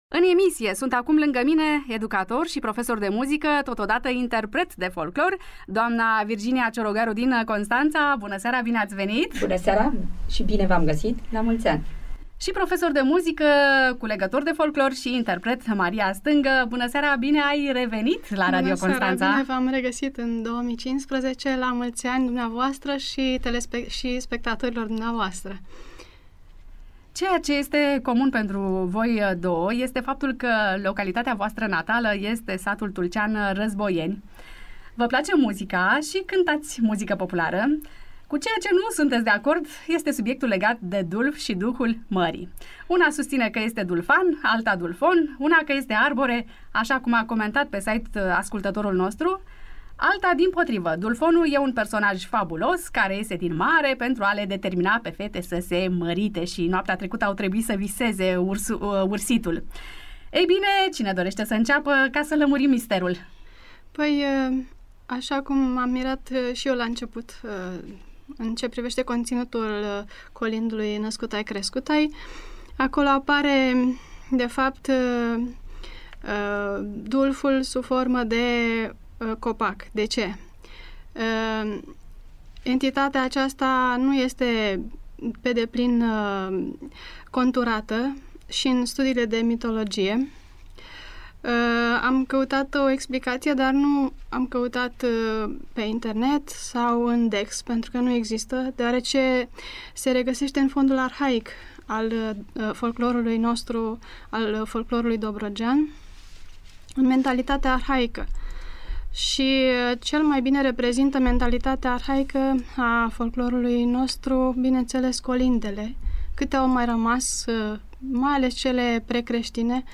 Aceasta a explicat, la Radio Constanța, în cadrul emisiunii de folclor „Interferențe dobrogene”, ce este Dulfonul și ce înseamnă „Ileana ghindosește”.
are o voce caldă, plină de sensibilitate